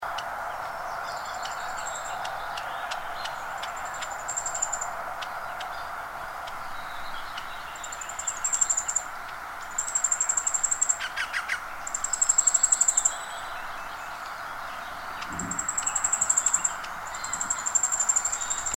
Käenpiika / Wryneck (Jynx torquilla)
Käenpiika Jynx torquilla, poikasten ja emon ääniä 5.7.2003, Espoo (MP3, 220 KB)